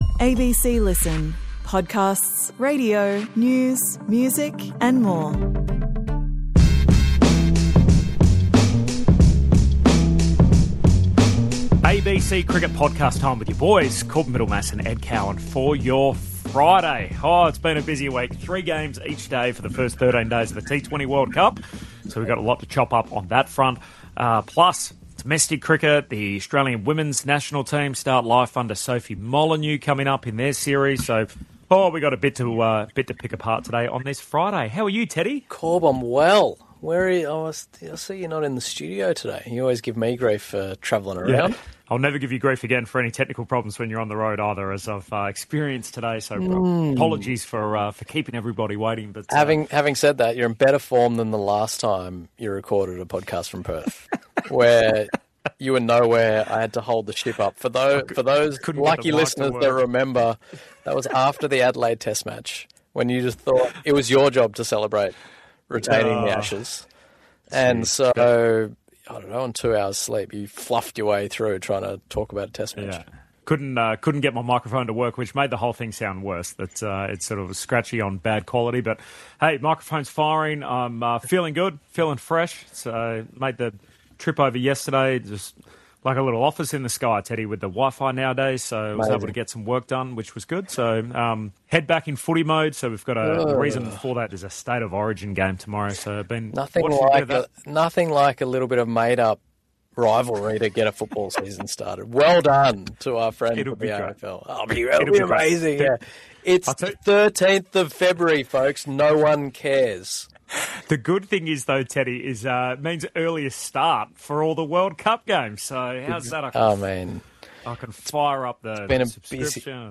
The podcast records just as the Australia and Zimbabwe match begins so the boys ponder whether Smith is a like for like replacement for Marsh at the top of the order or if he fits in elsewhere should the skipper fly home.